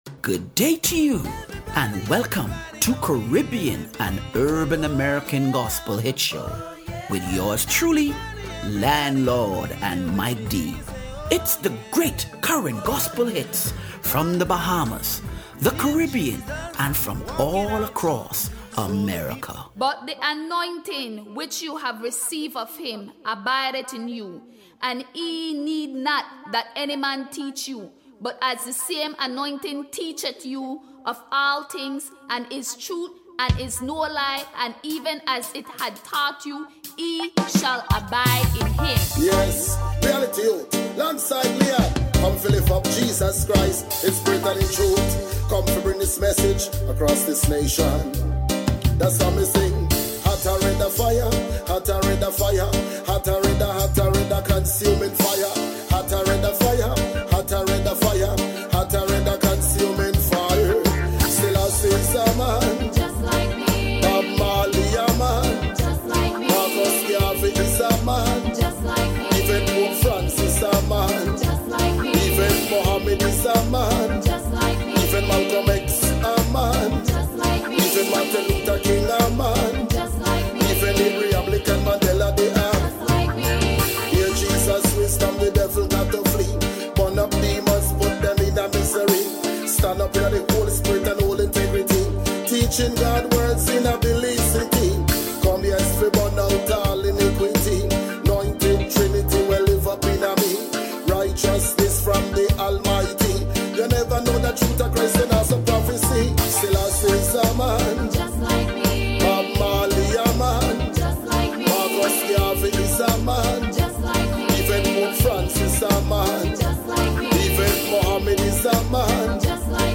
Caribbean and Urban American Gospel Hits - October 12 2025